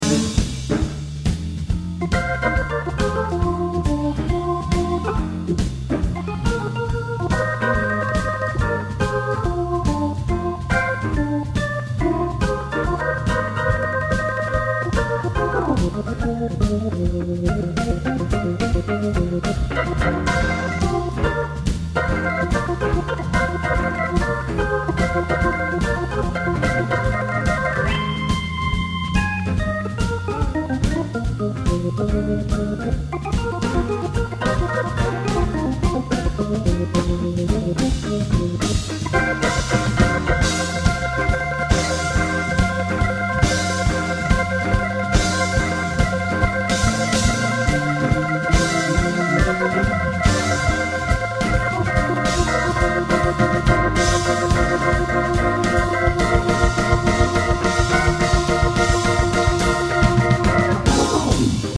à la guitare